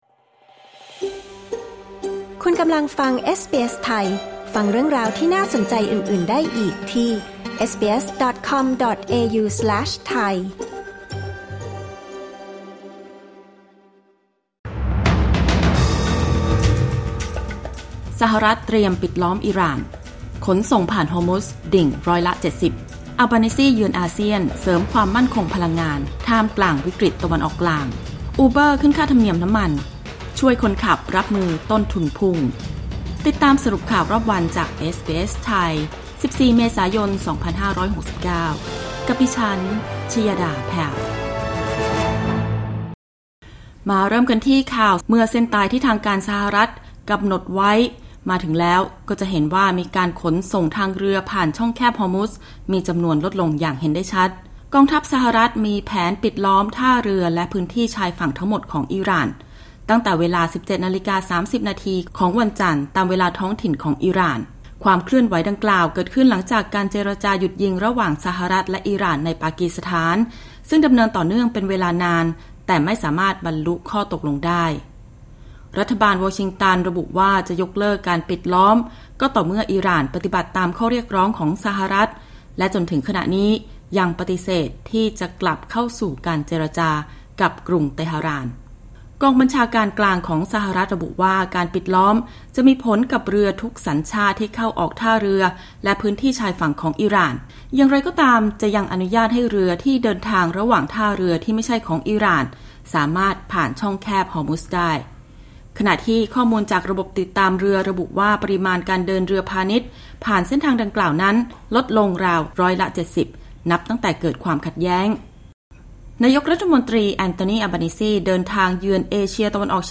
สรุปข่าวรอบวัน 14 เมษายน 2569